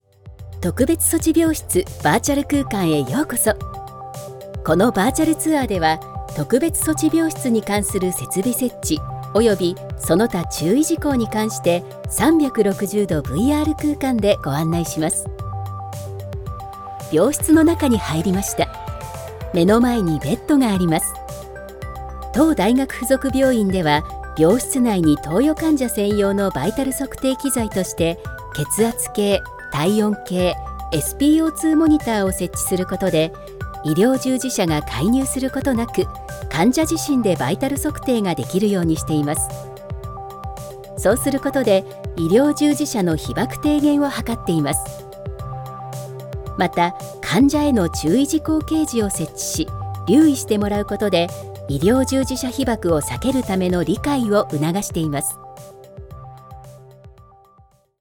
Narration médicale
Sa voix douce, claire et attachante est très polyvalente, ce qui en fait le choix incontournable de nombreuses grandes entreprises et organismes gouvernementaux.